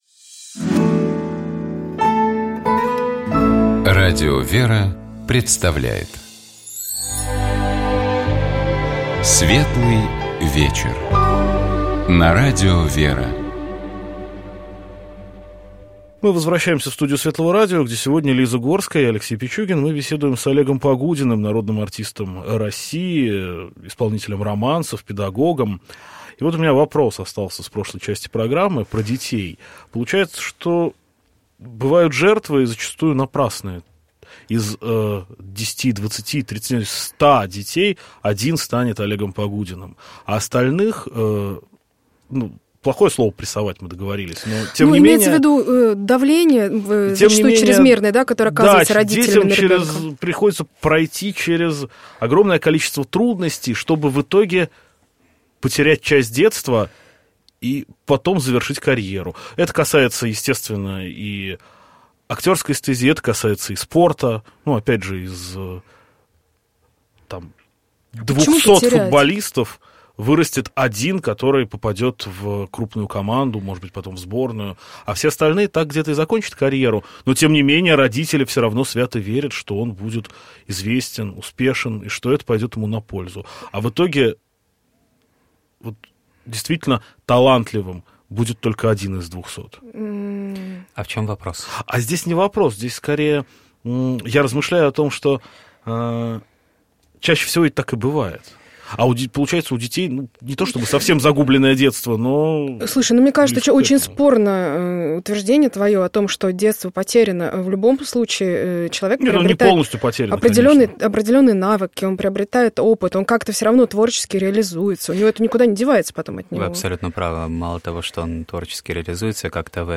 У нас в гостях был Народный артист России, российский певец, педагог Олег Погудин.